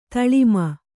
♪ taḷima